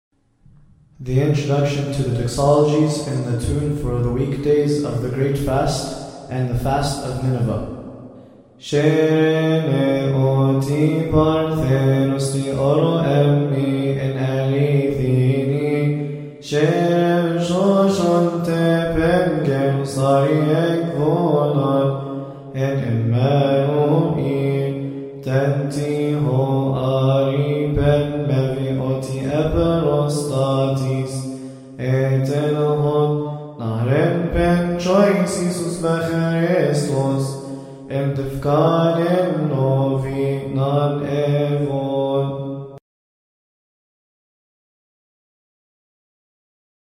All hymns must be chanted according to the Higher Institute of Coptic Studies.
Weekdays and